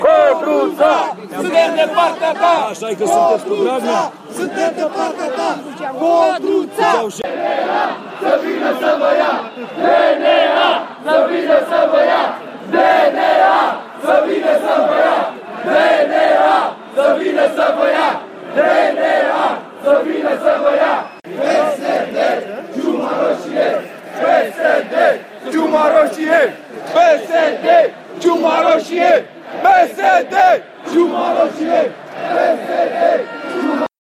Proteste și lozinci la demonstrația de la București